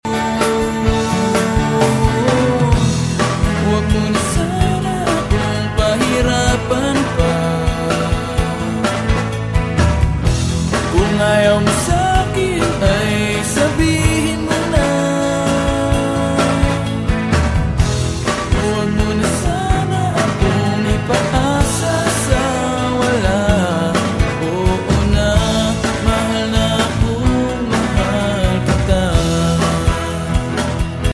You Just Search Sound Effects And Download. tiktok funny sound hahaha Download Sound Effect Home